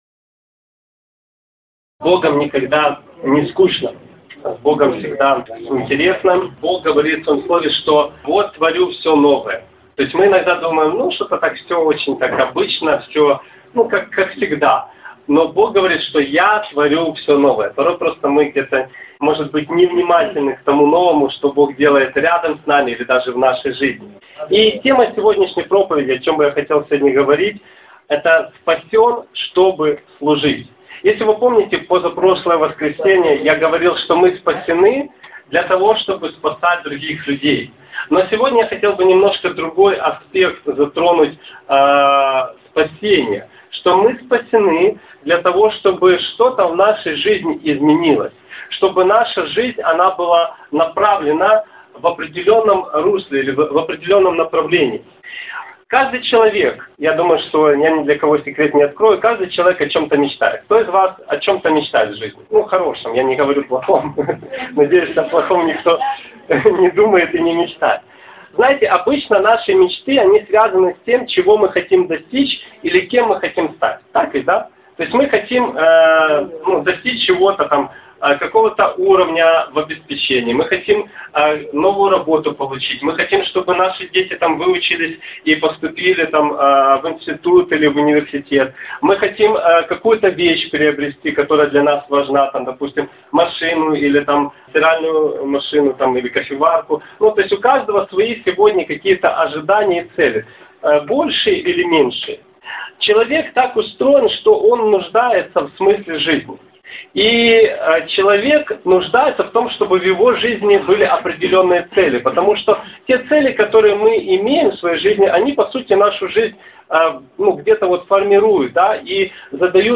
Latest Sermon